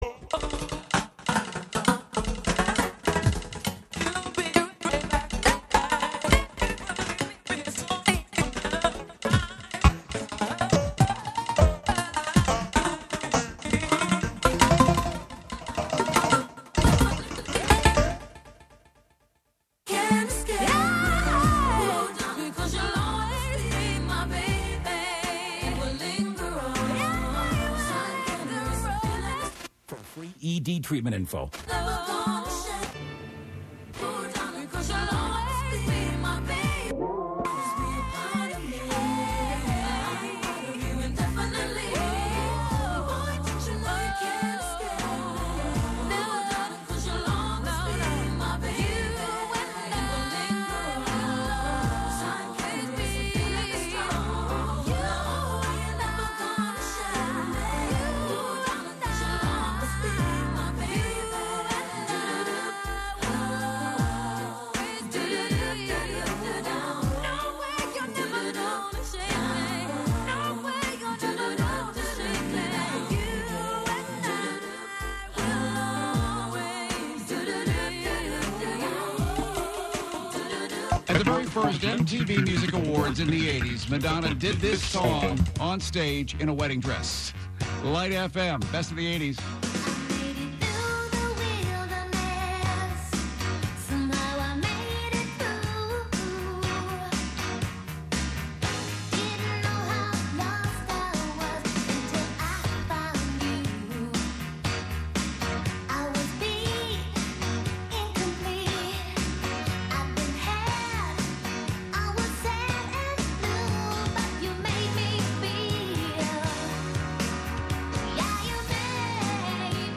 11am Live from Brooklyn, New York
composer-performer